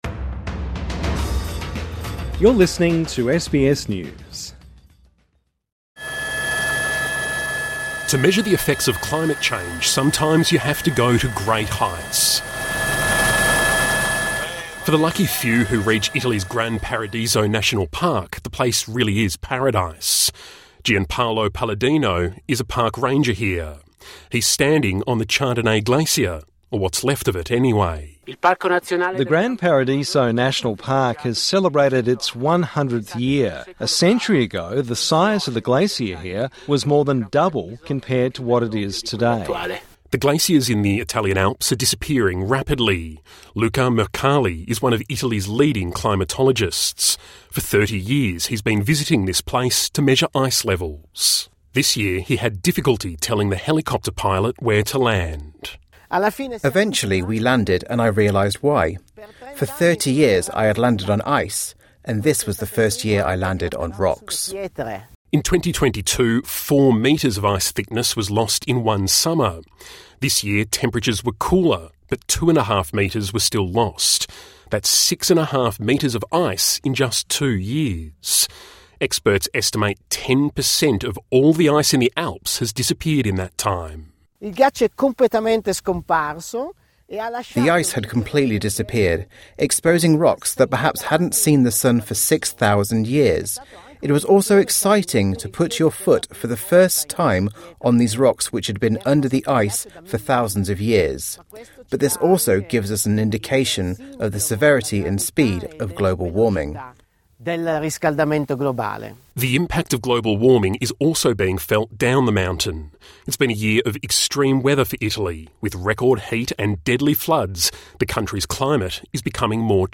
TRANSCRIPT (Sound Effect – helicopter) To measure the effects of climate change, sometimes you have to go to great heights…